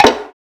snare (dancehall).wav